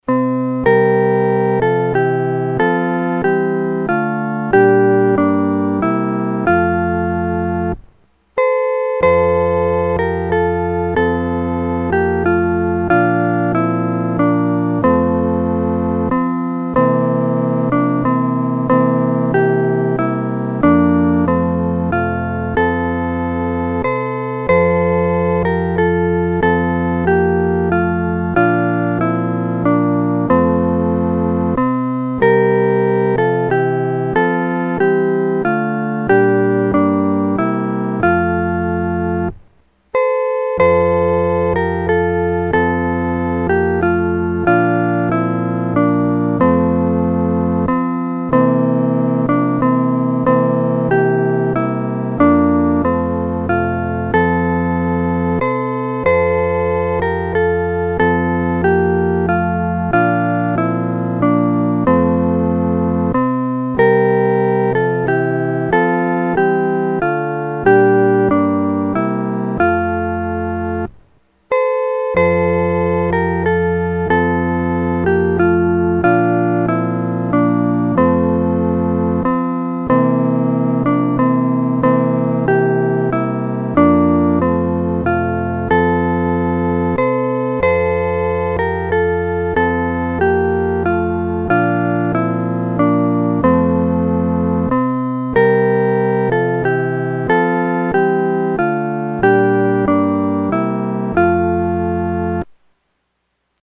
合奏（四声部）
在马槽里-合奏（四声部）.mp3